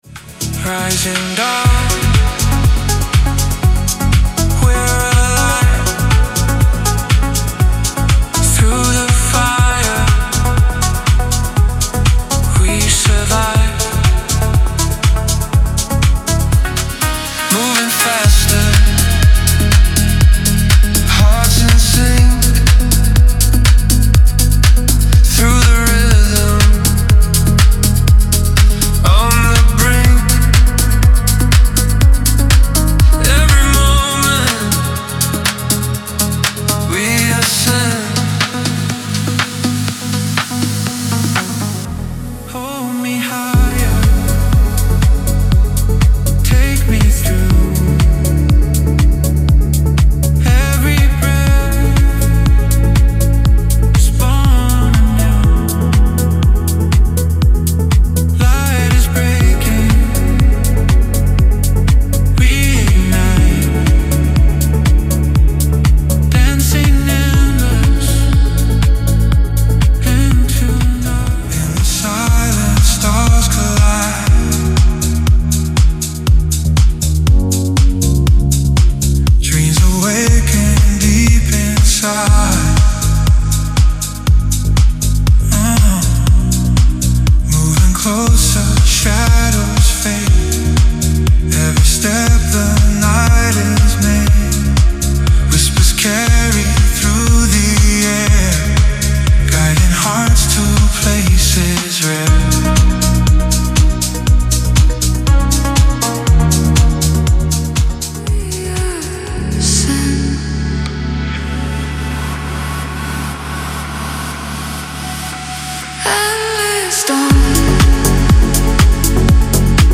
Type: Samples
8-Bit Deep House House Melodic Techno Vocals
• 36 Vocal Stems
• 37 Vocal Phrases
• 122 Bpm